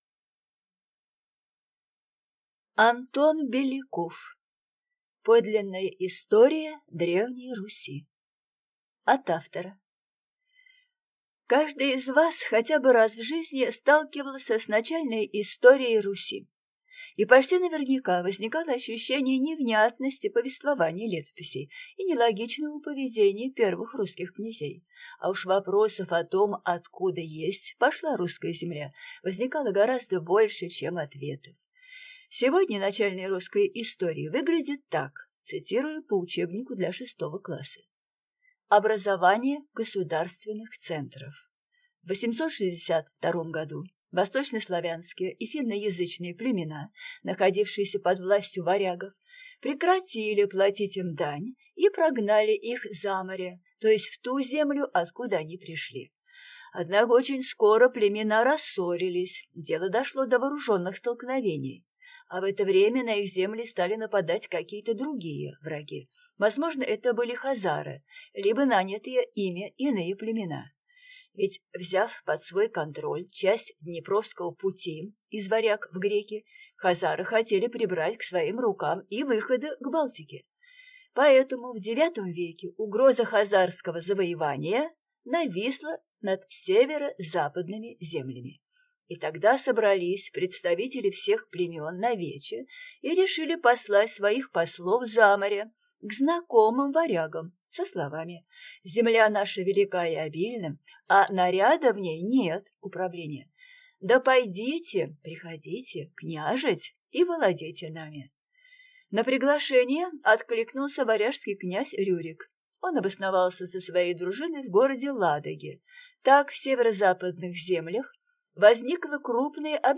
Аудиокнига Подлинная история Древней Руси | Библиотека аудиокниг
Прослушать и бесплатно скачать фрагмент аудиокниги